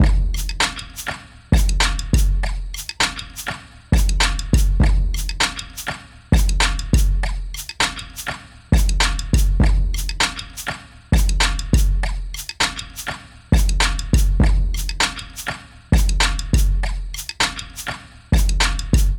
OSHI_drum_loop_timbo_100.wav